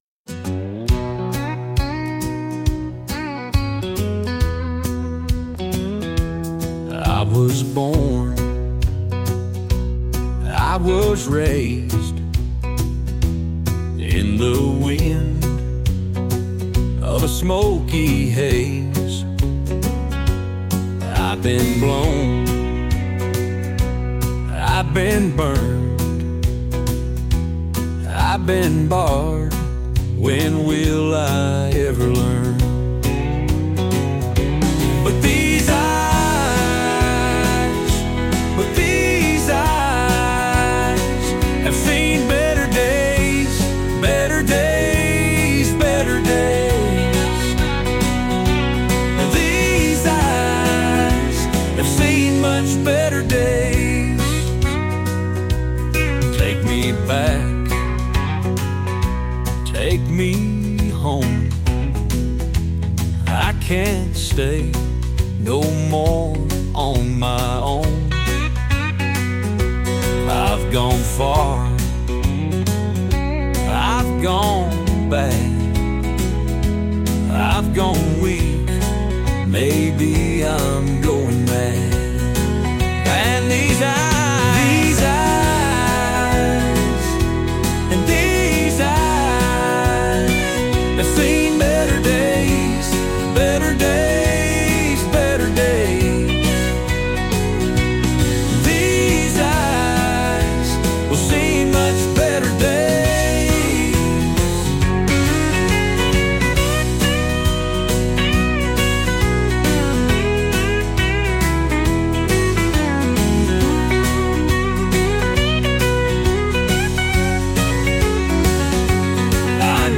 a melancholic yet deeply reflective country song